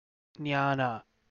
Captions English Kannada pronunciation of "jnaana"
Kn-jnaana.ogg